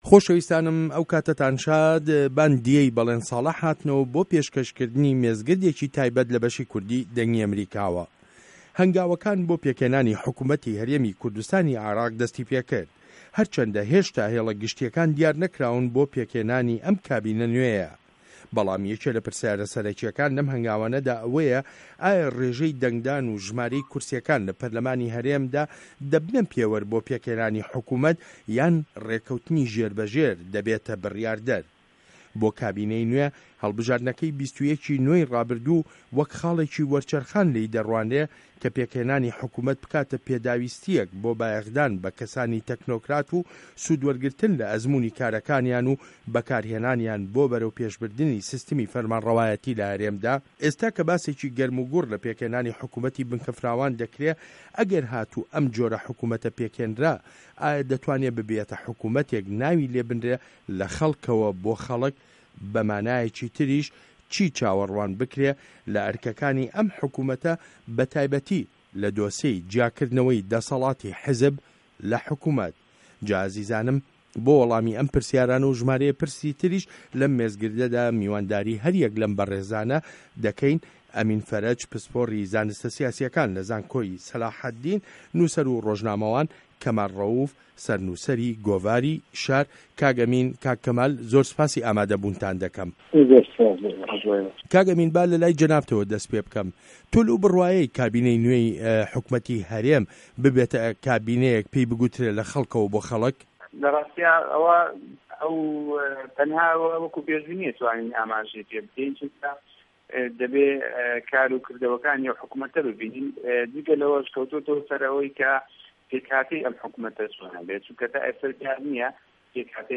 مێزگرد : فاکته‌ری متمانه‌ له‌ پێکهێنانی حکومه‌تی هه‌رێمی کوردستانی عێراقدا